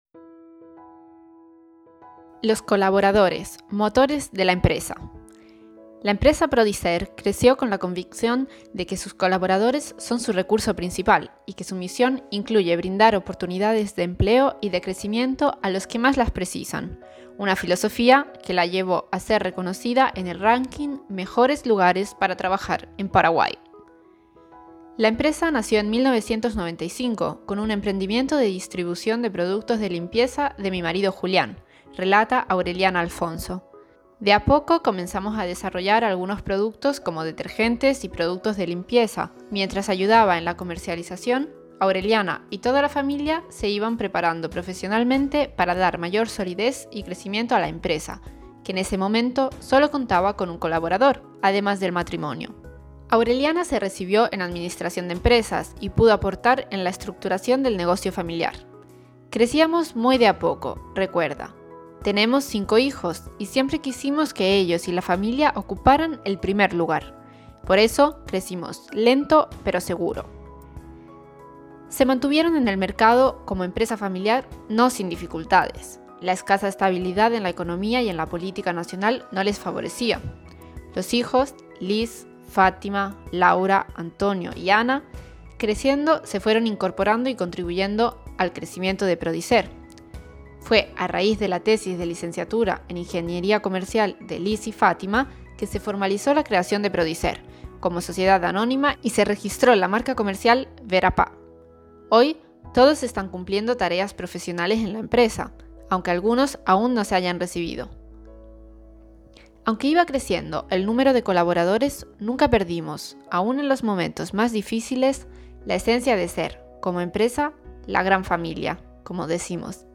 Persona e famiglia > Audioletture